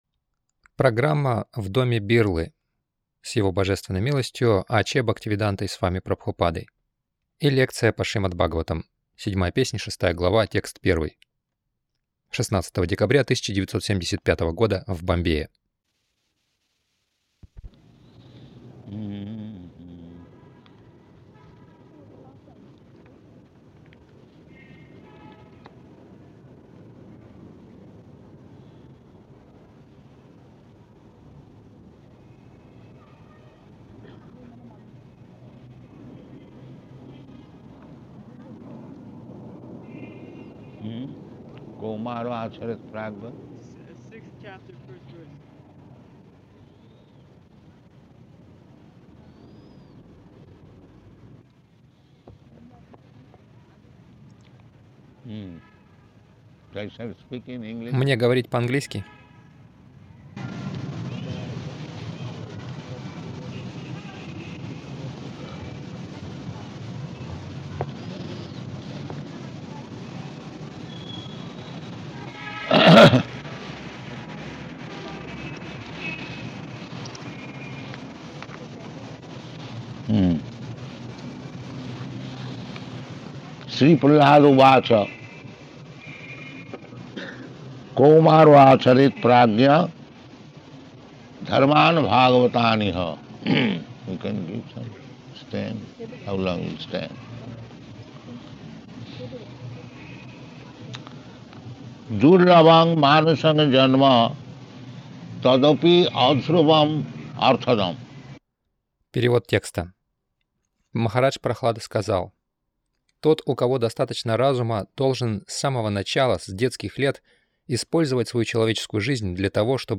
ШБ 07.06.01 в доме Бирлы — Не оставайтесь во тьме
Милость Прабхупады Аудиолекции и книги 16.12.1975 Шримад Бхагаватам | Бомбей ШБ 07.06.01 в доме Бирлы — Не оставайтесь во тьме Загрузка...